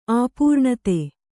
♪ āpūrṇate